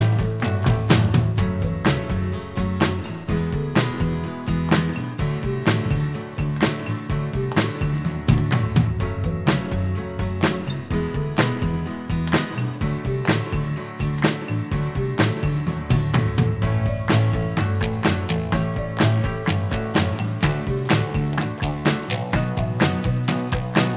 Minus Electric Guitar Rock 3:39 Buy £1.50